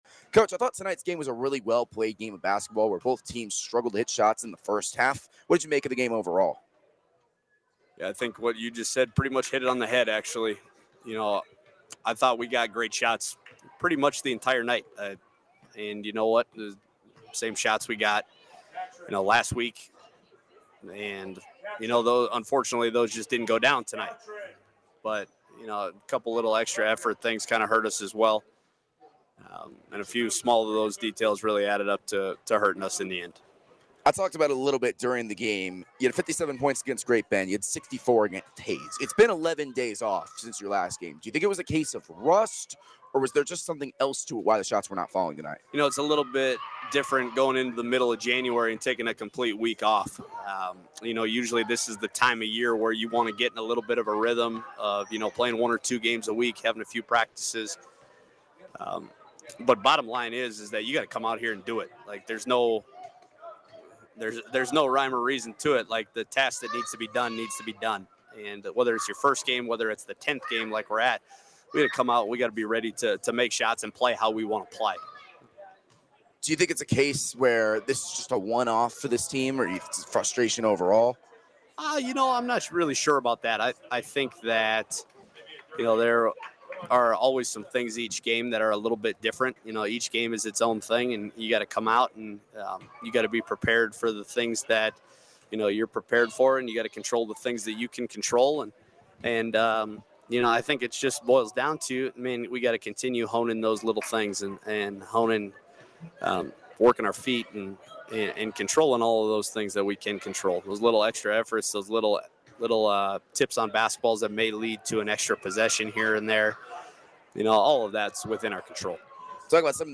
Audio Recap